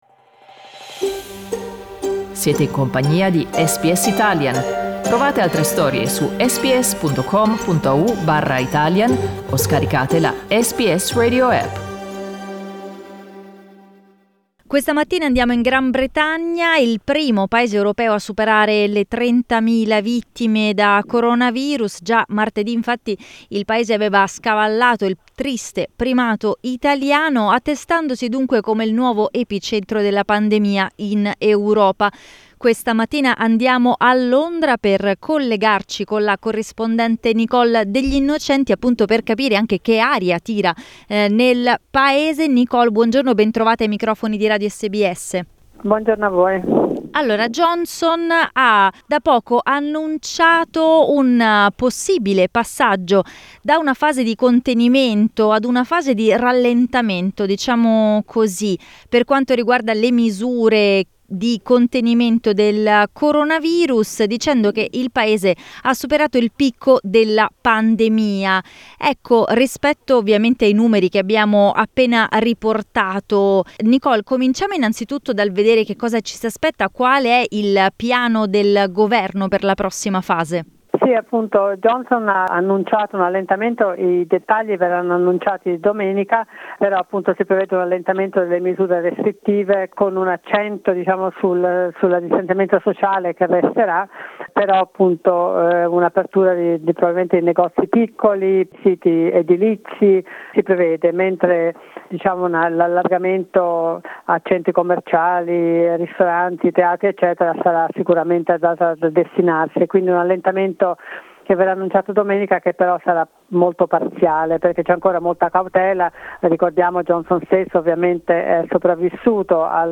I dettagli nel collegamento da Londra